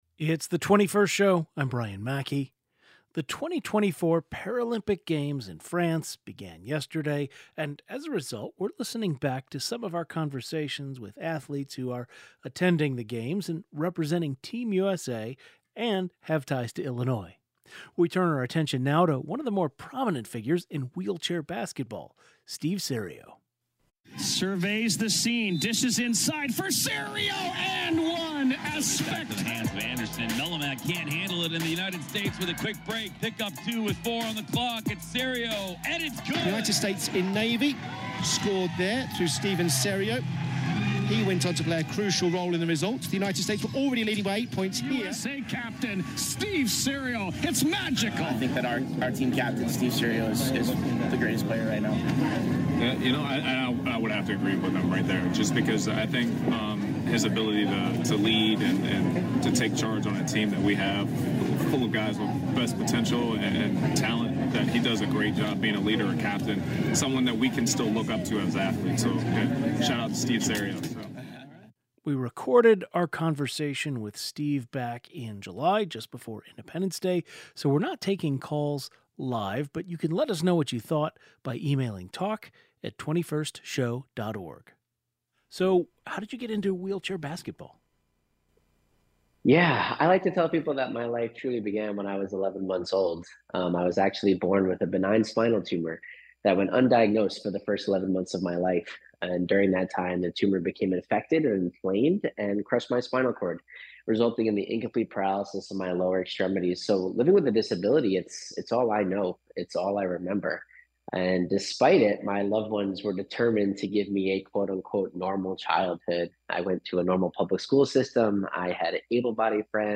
The 2024 Paris Paralympic Games have officially begun and today, we are speaking with Paralympian Steve Serio — one of the most prominent figures in wheelchair basketball.